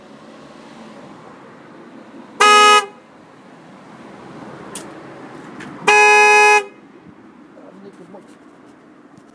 Voiture arrêt
son voiture arret.wav